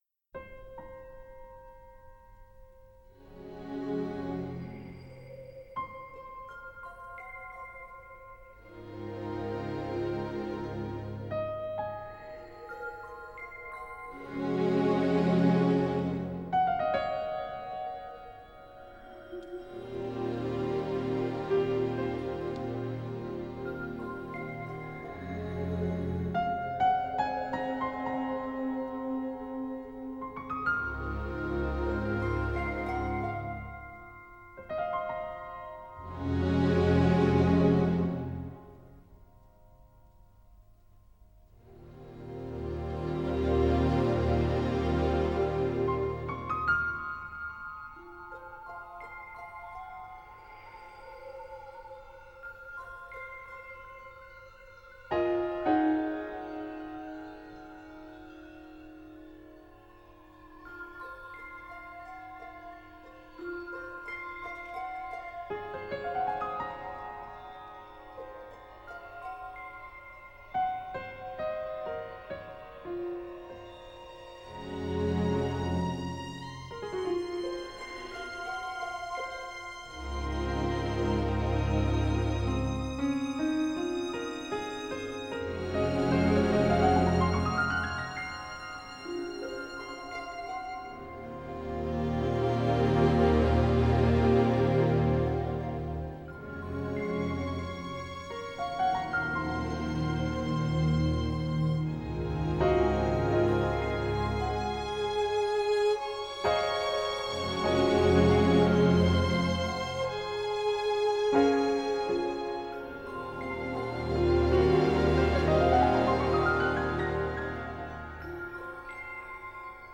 Жанр: Soundtrack